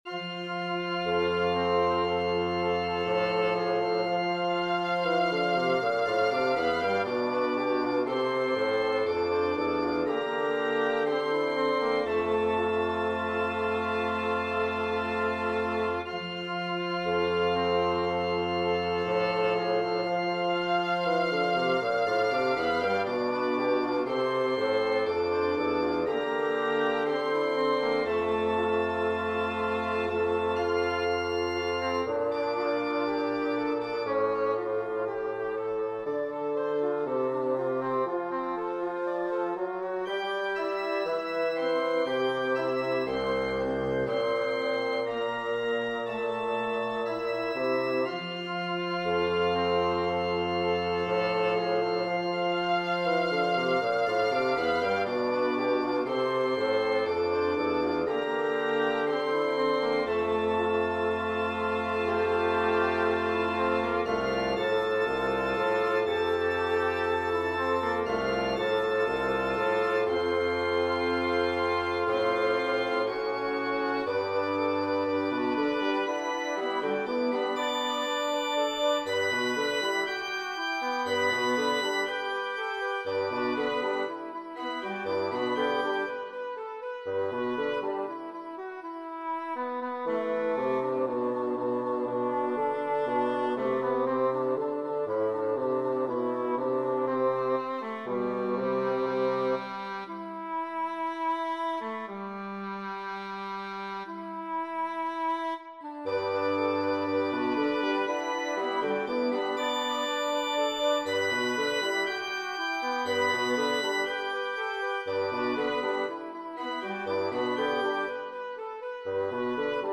This represents literally decades of guitar foolery.